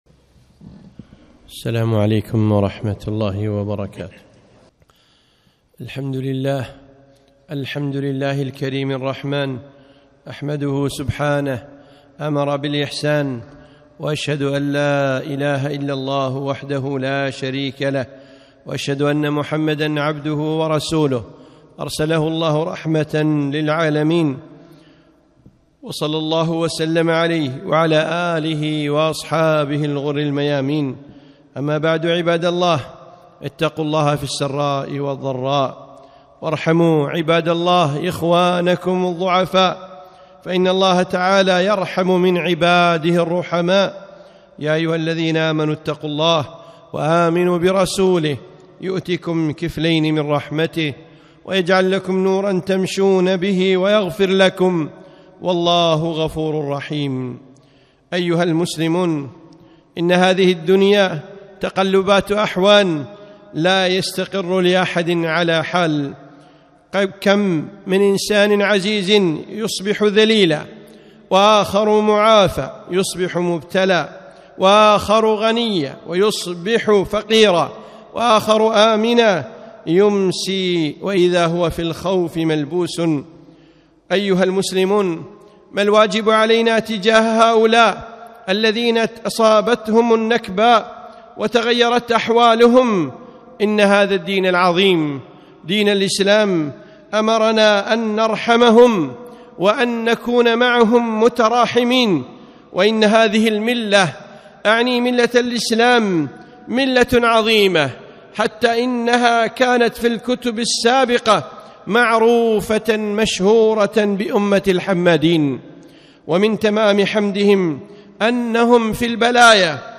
خطبة - ارحموا ثم ارحموا